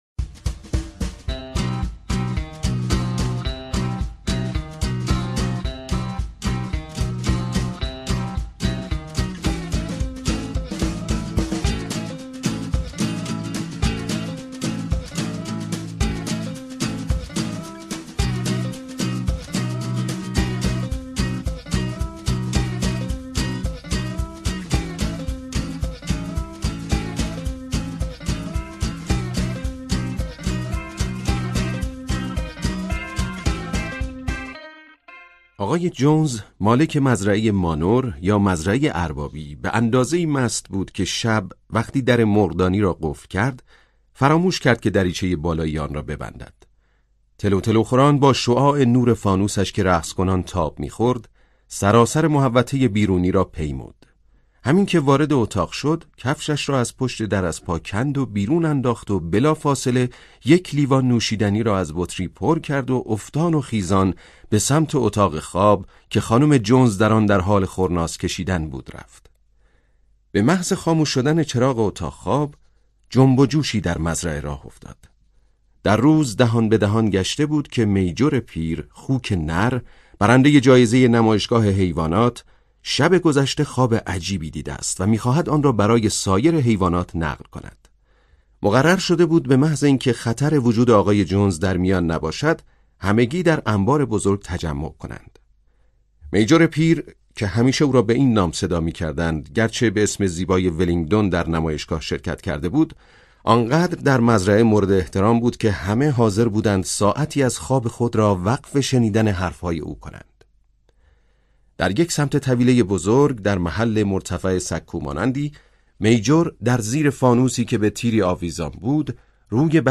کتاب صوتی مزرعه حیوانات "قلعه حیوانات"